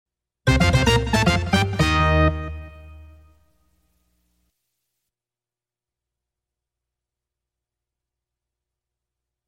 Звуки логотипа